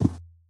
sounds / mob / camel / step2.ogg
step2.ogg